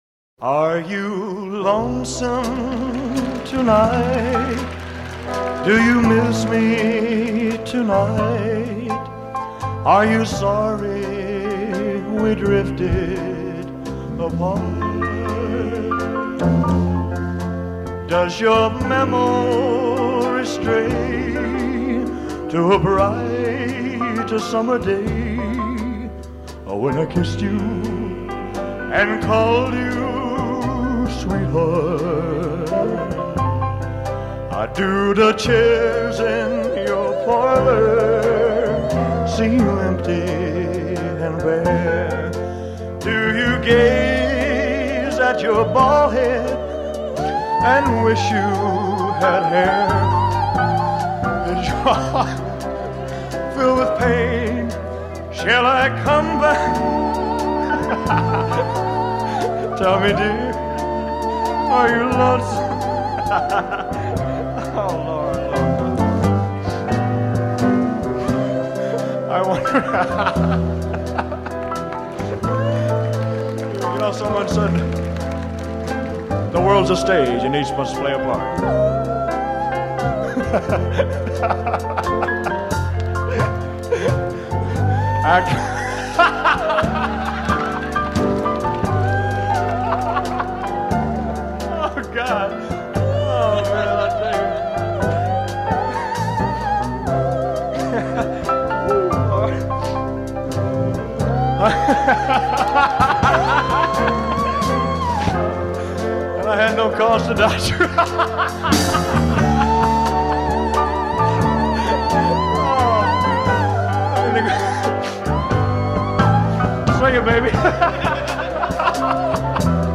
The fun starts about 1/4th of the way through the song.